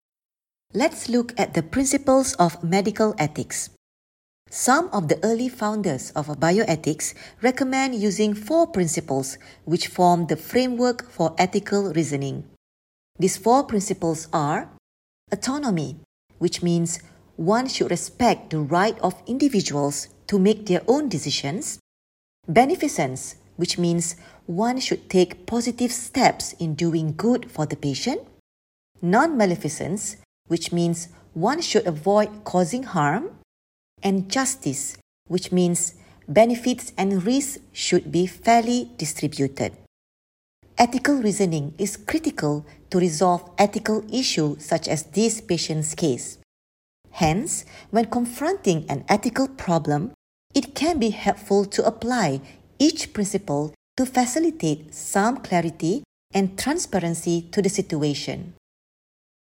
Narration audio (MP3) Contents Home What is Medical Ethics?